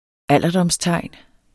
Udtale [ ˈalˀʌdʌms- ]